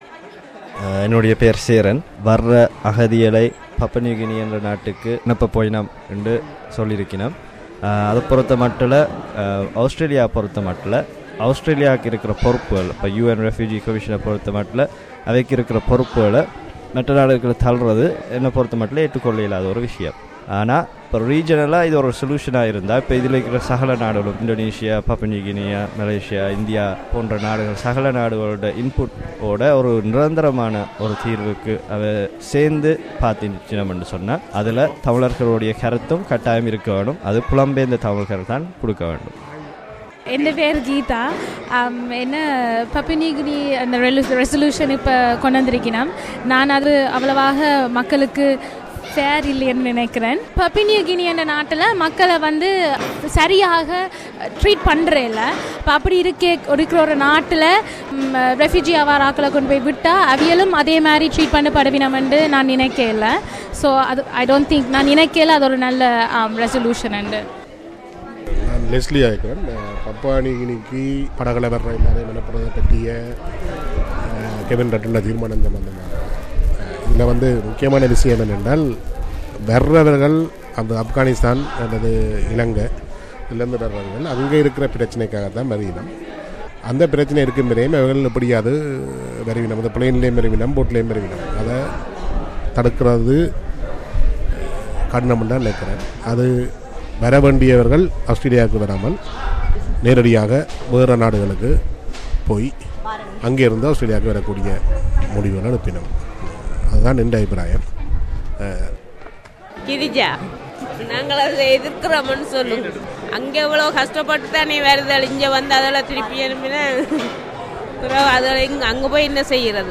vox populi